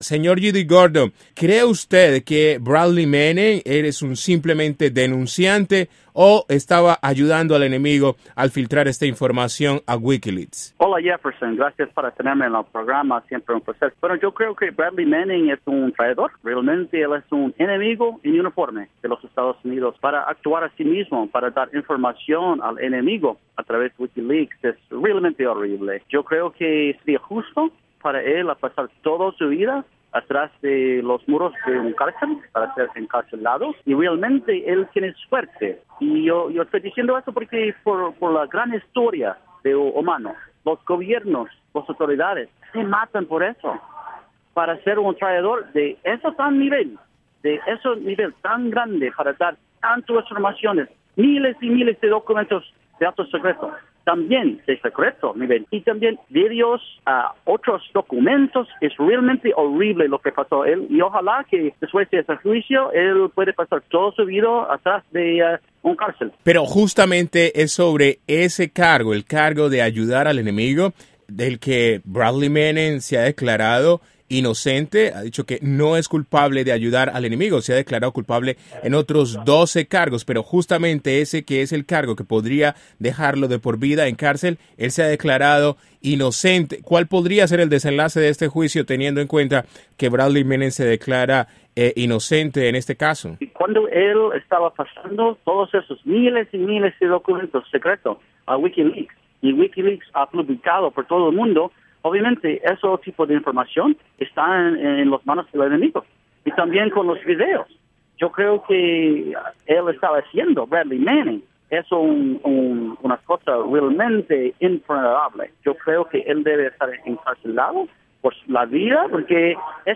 Entrevista: "Manning es un traidor"